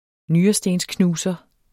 Udtale [ ˈnyːʌsdens- ]